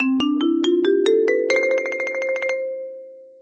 marimba_scale_up.ogg